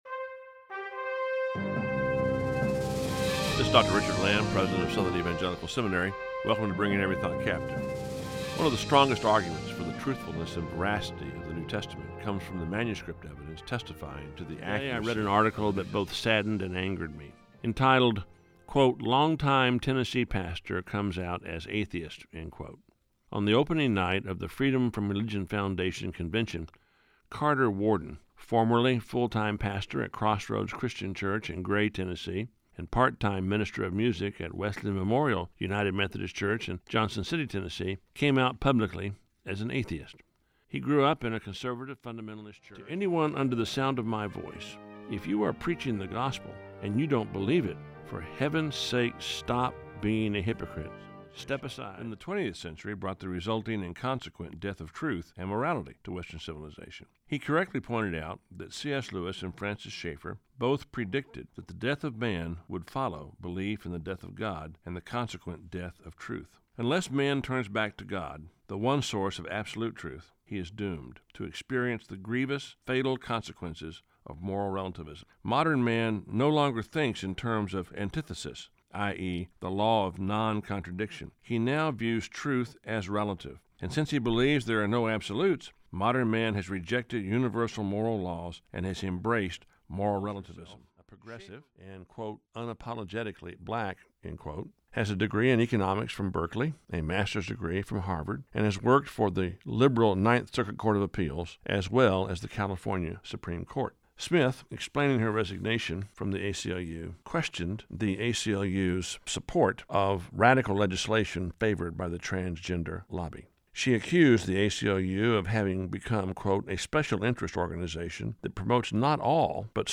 can be heard in the nationally syndicated daily radio commentary